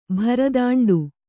♪ bharadāṇḍu